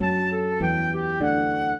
flute-harp
minuet3-7.wav